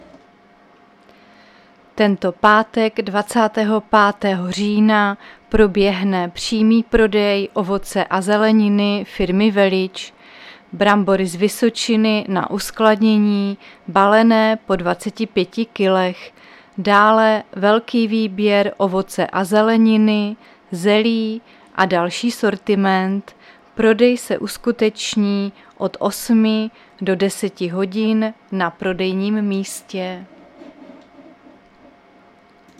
Záznam hlášení místního rozhlasu 23.10.2024
Zařazení: Rozhlas